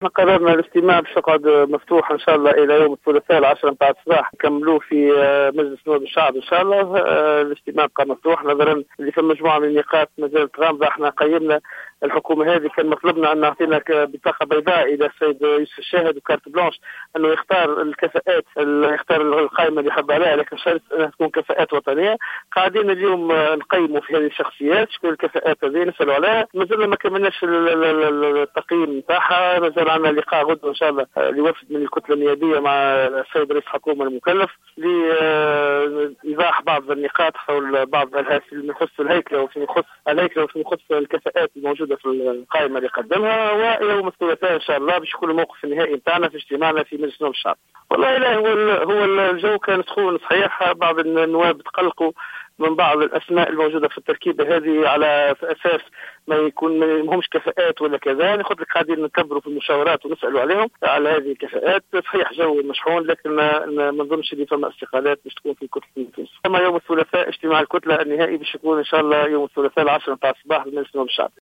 أكد رئيس كتلة حركة نداء تونس سفيان طوبال في تصريح لمراسلة الجوهرة "اف ام" على هامش اجتماع لها اليوم في الحمامات أن وفدا من الكتلة سيلتقي غدا برئيس الحكومة المكلف يوسف الشاهد.